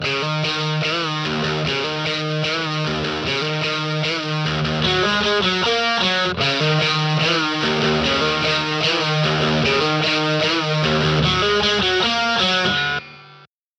я сыграл на страте
guitarz.mp3